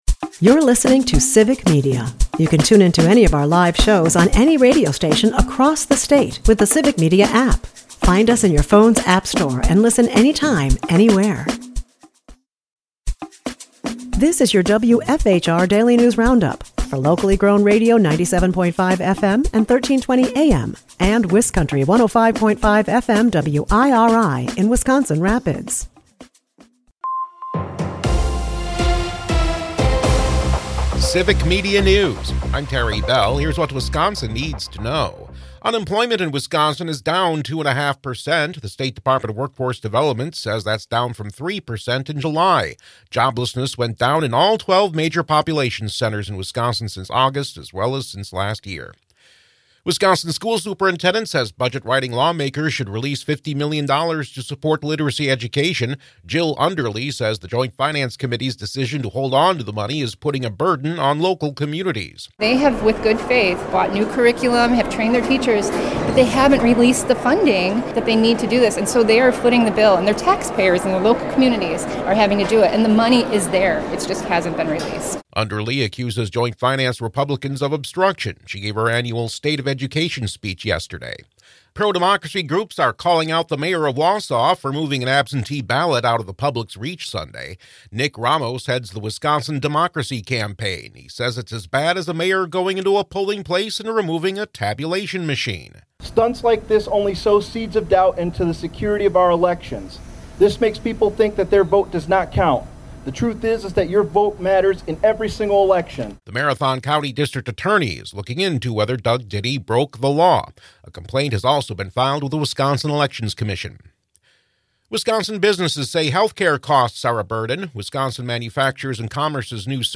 The WFHR Daily News Roundup has your state and local news, weather, and sports for Wisconsin Rapids, delivered as a podcast every weekday at 9 a.m. Stay on top of your local news and tune in to your community!